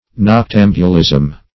noctambulism - definition of noctambulism - synonyms, pronunciation, spelling from Free Dictionary
Noctambulism \Noc*tam"bu*lism\, n.